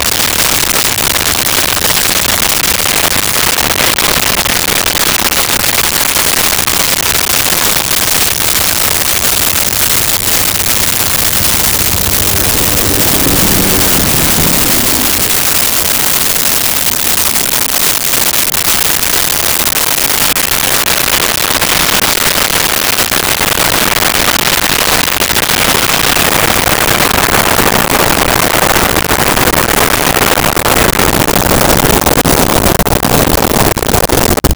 Military Helicopter Medium By
Military Helicopter Medium By.wav